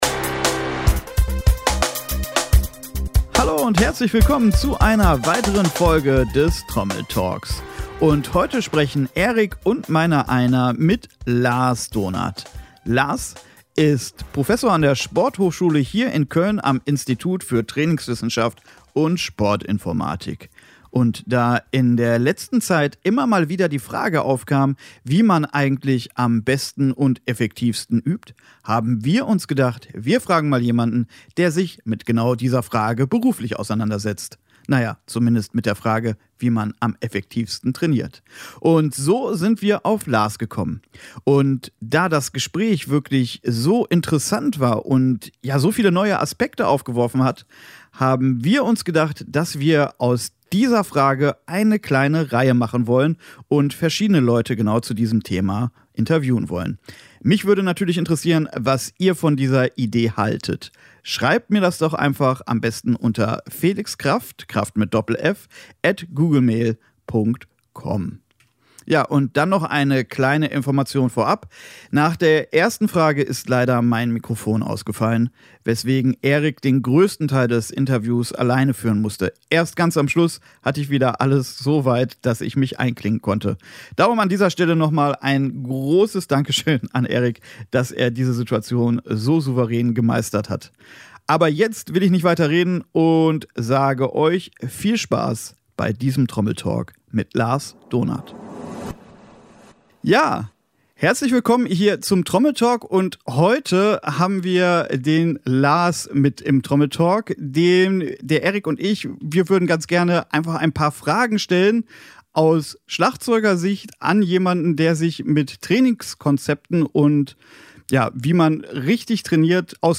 Wir fragen einen Trainingswissenschaftler zum Thema Üben – Der Trommel Talk Podcast Folge 8 Teil 1